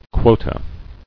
[quo·ta]